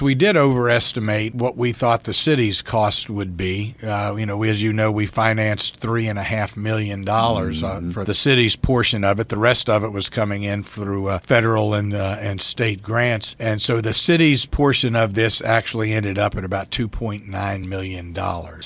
Mayor Ray Morriss talked to WCBC this week, saying what the city estimated they would be paying toward the project ended up higher than needed, giving them a bit of a savings…